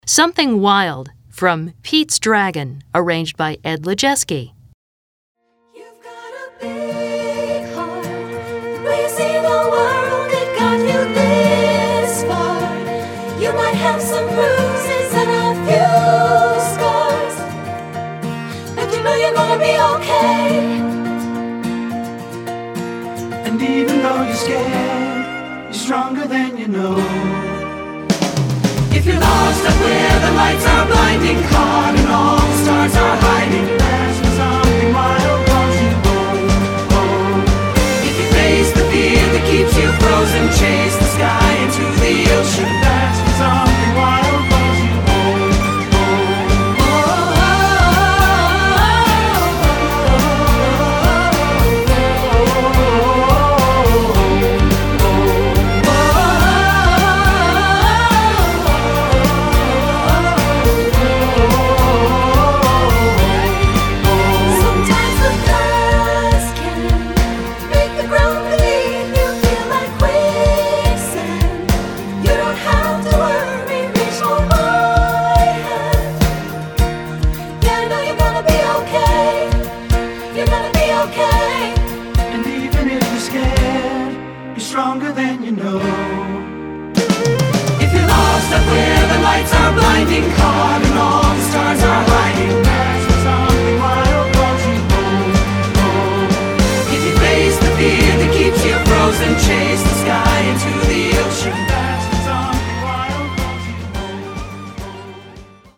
Choral Movie/TV/Broadway
this exuberant song